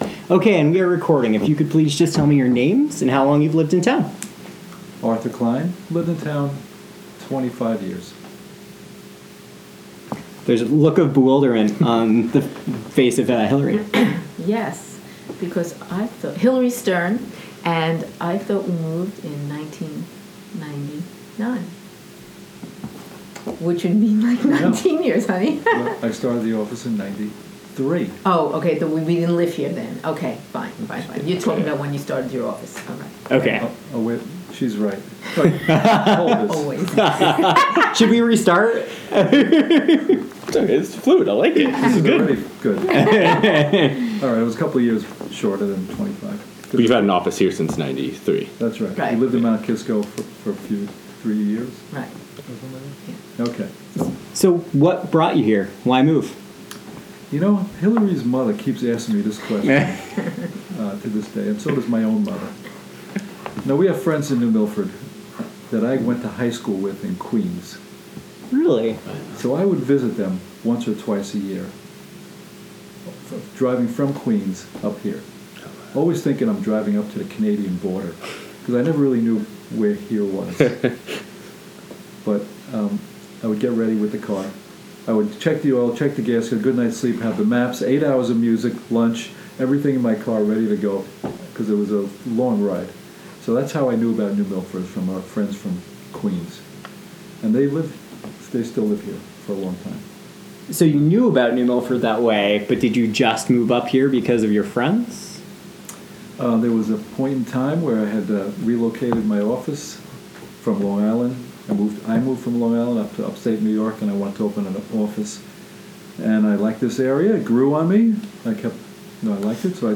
Oral History
Location New Milford Public Library